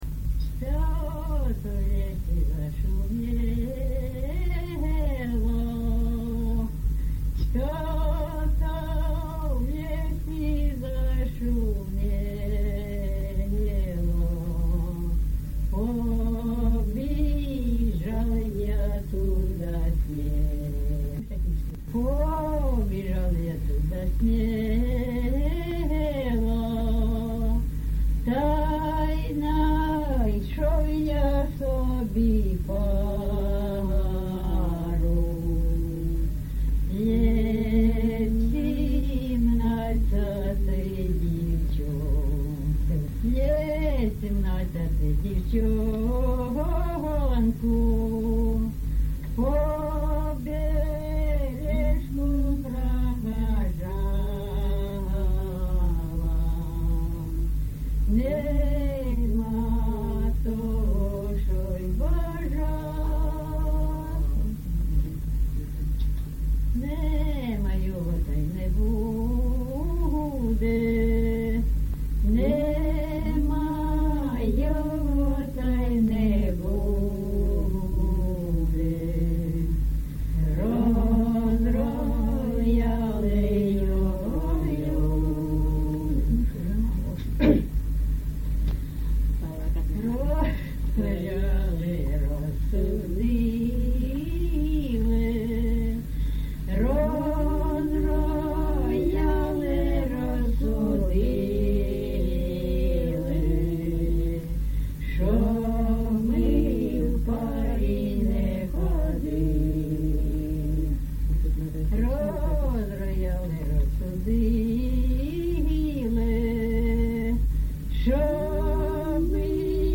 ЖанрПісні з особистого та родинного життя
Місце записус. Званівка, Бахмутський район, Донецька обл., Україна, Слобожанщина